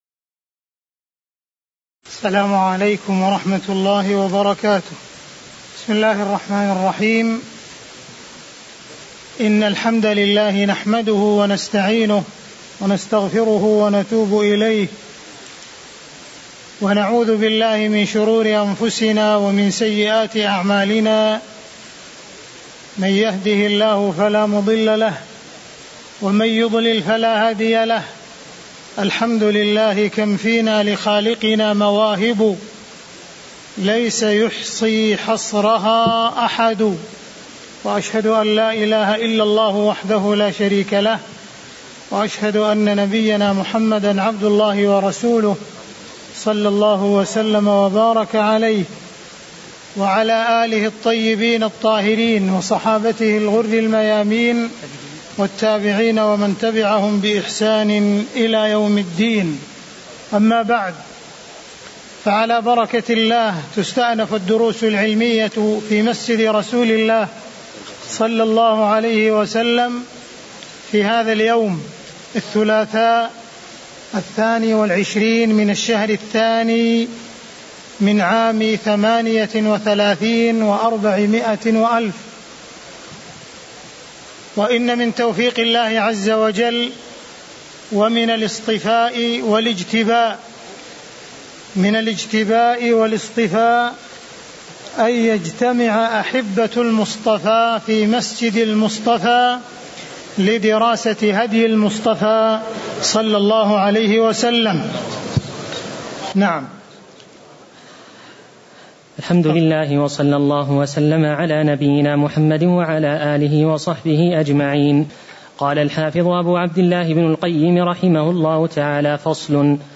تاريخ النشر ٢٢ صفر ١٤٣٨ هـ المكان: المسجد النبوي الشيخ: معالي الشيخ أ.د. عبدالرحمن بن عبدالعزيز السديس معالي الشيخ أ.د. عبدالرحمن بن عبدالعزيز السديس من قوله: والمقصود أن الله سبحانه إختار من جنس المخلوقات أطيبه (017)